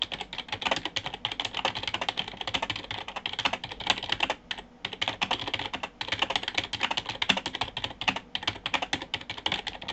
typing sound
keyboard typing sound effect free sound royalty free Memes